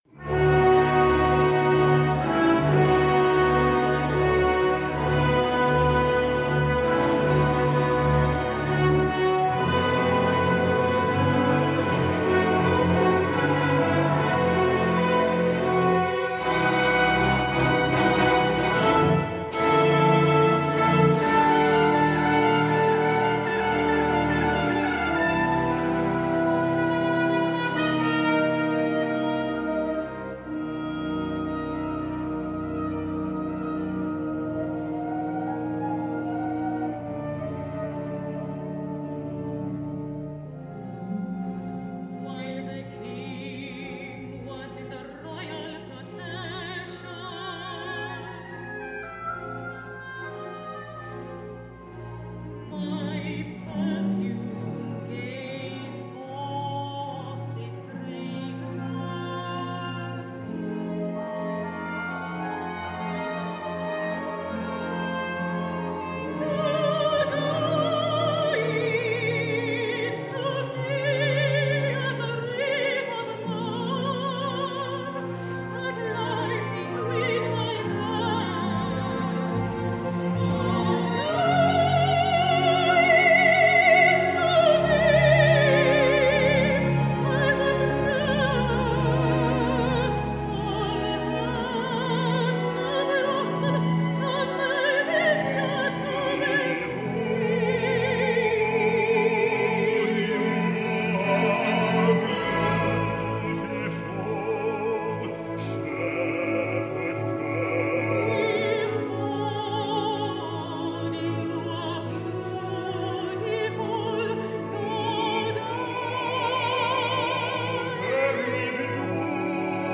Mezzo
Baritone
Tenor